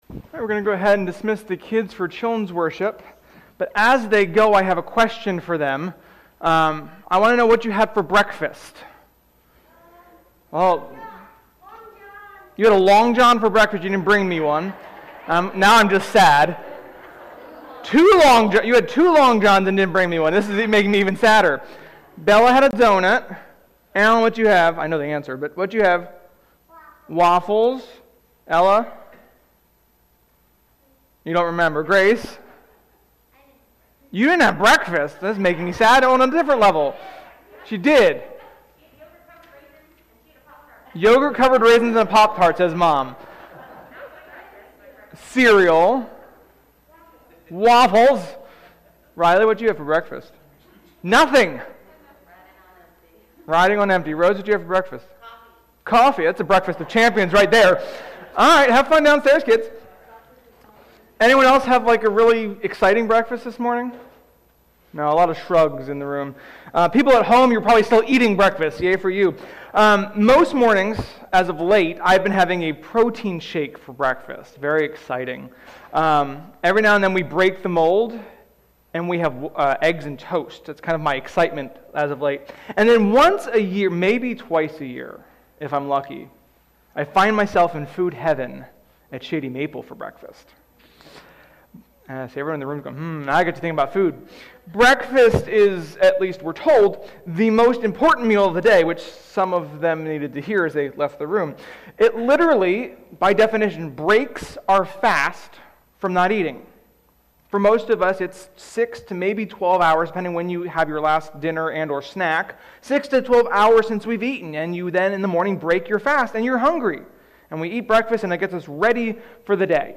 Sermon-3.21.21.mp3